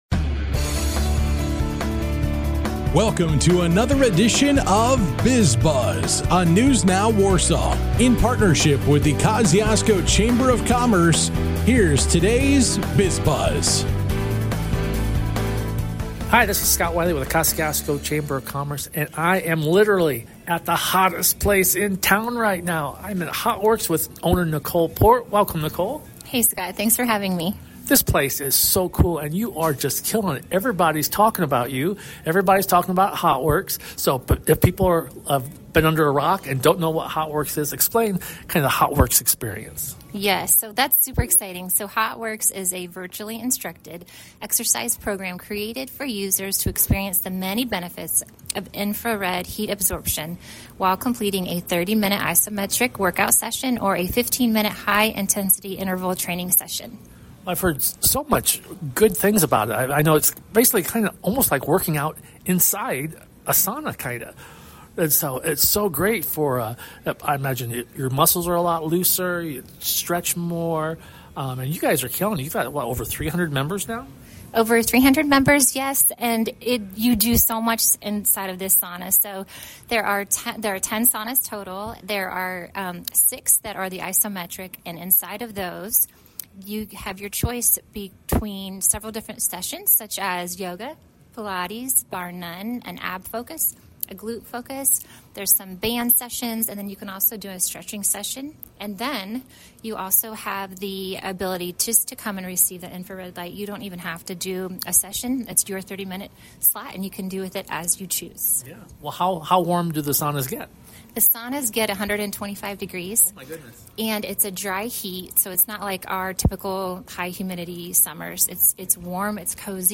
A chat with Hotworx!